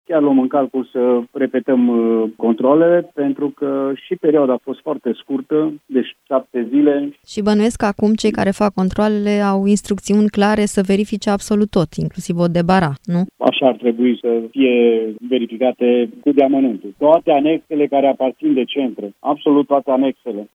Toate cele peste 90 de azile din Mureș vor fi controlate din nou – spune subprefectul Nicolae Pălășan: